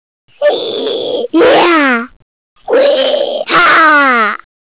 Wer nochmal mitraten möchte, kann sich ja zuerst die Tierstimmen anhören.